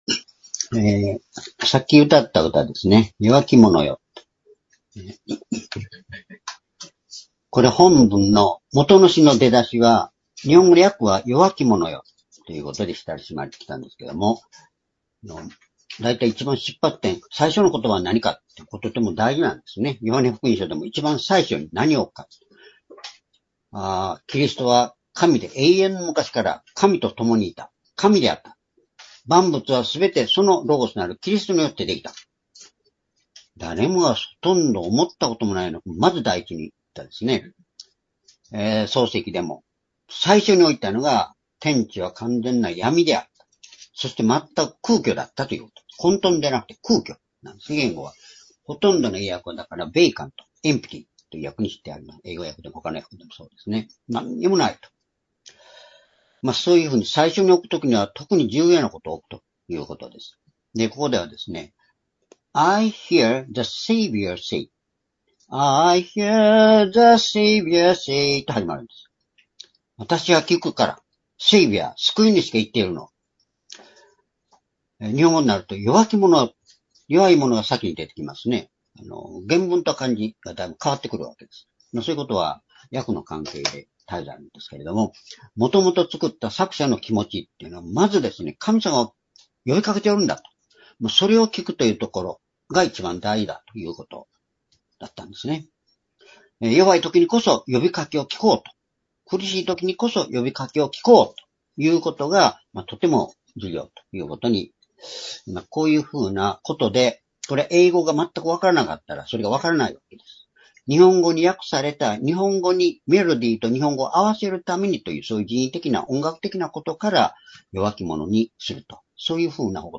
「イエスの愛、弟子の愛」ヨハネ21章1～7節-2025年5月25日(主日礼拝)